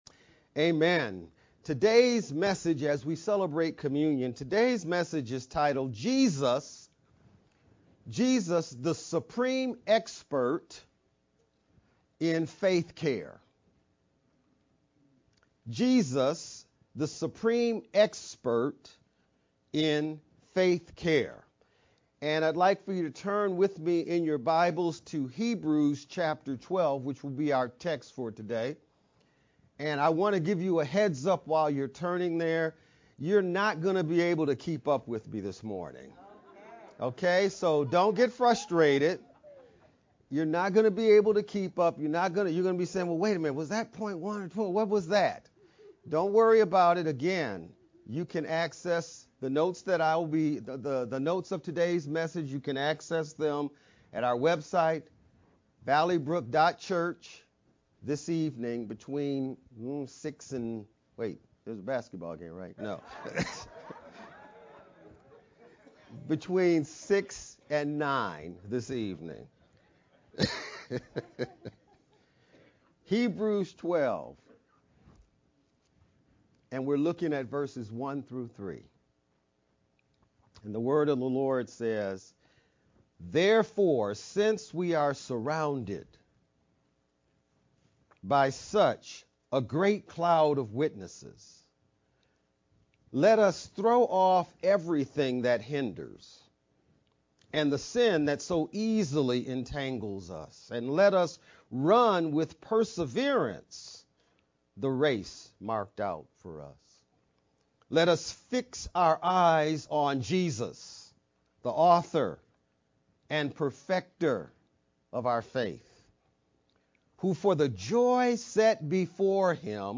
VBCC-Sermon-3-26-edited-sermon-only-Mp3-CD.mp3